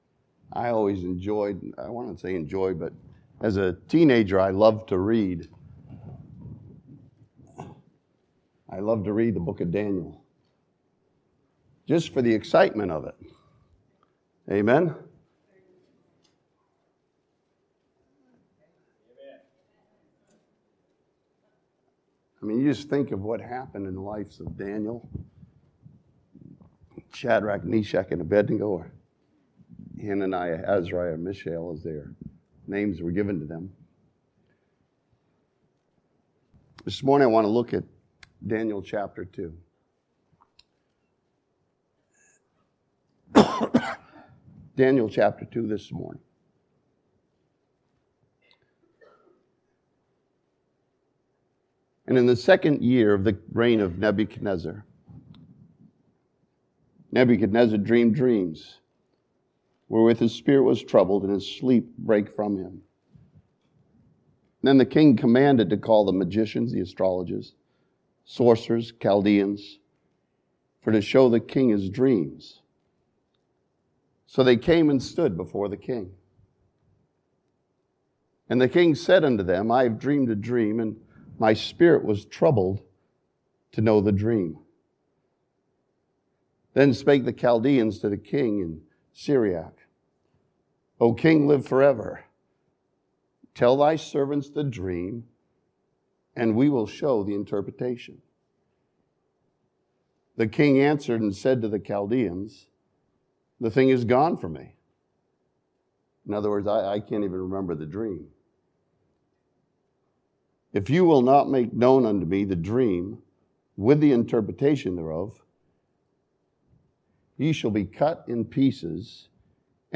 This sermon from Daniel chapter 2 challenges believers to live in purity and faith like Daniel and his friends.